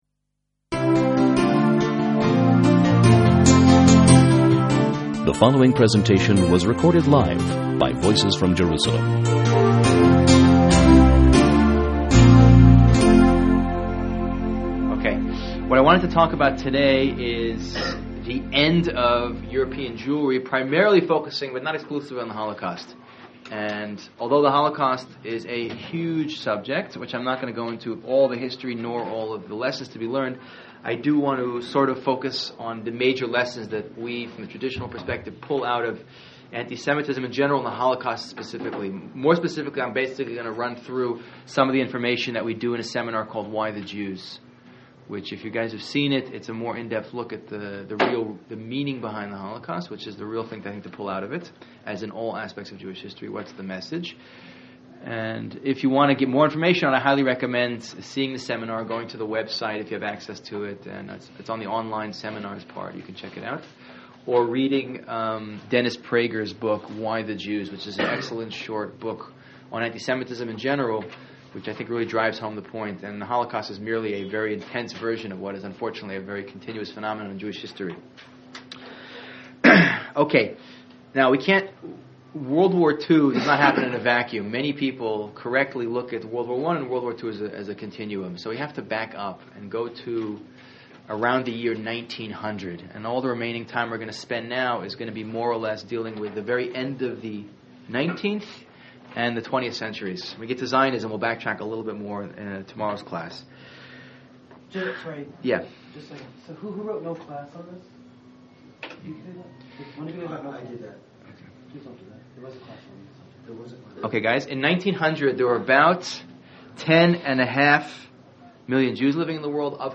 Contribute: Add your Summary, Outline or Thoughts on this Lecture Commenting is not available in this channel entry.